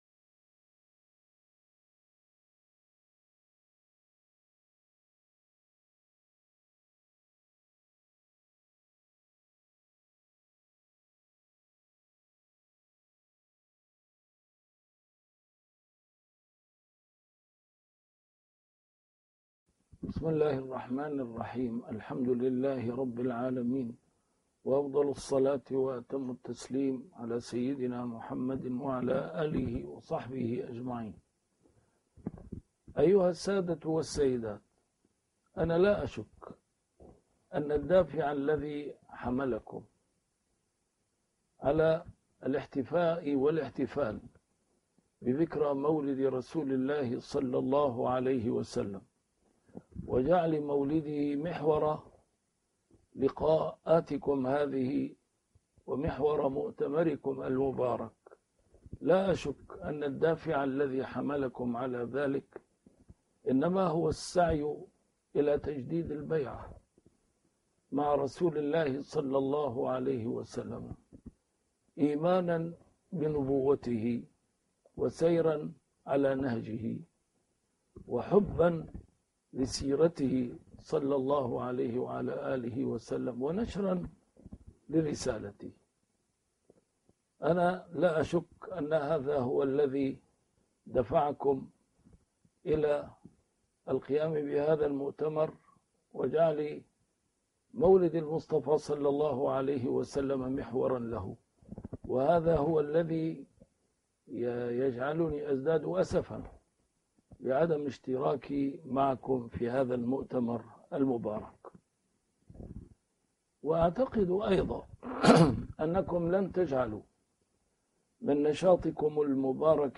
A MARTYR SCHOLAR: IMAM MUHAMMAD SAEED RAMADAN AL-BOUTI - الدروس العلمية - محاضرات متفرقة في مناسبات مختلفة - كلمة لمؤتمر _ لم يحضره _ عن المولد النبوي الشريف